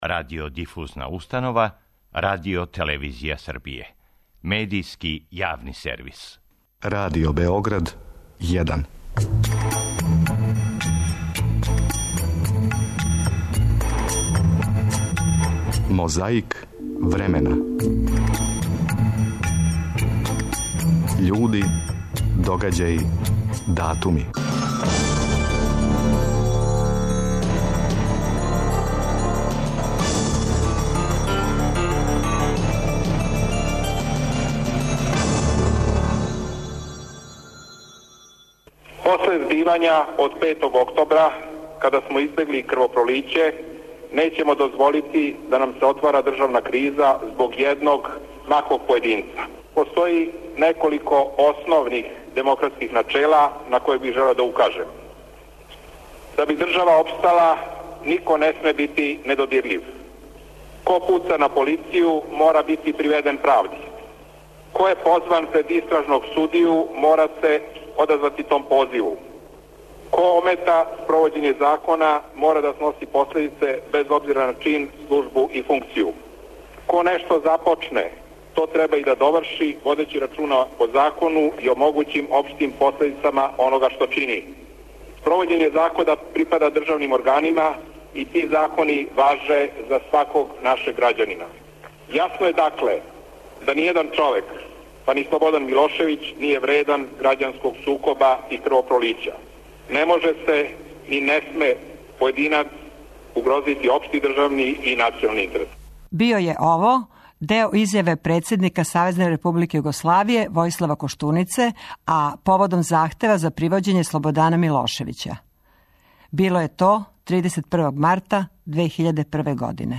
У Београду је 4. априла 1991. године одржана конференција за новинаре и новинарке поводом завршетка другог састанка шест републичких председника. Подсећамо шта су тада говорили Алија Изетбеговић, Фрањо Туђман и Слободан Милошевић.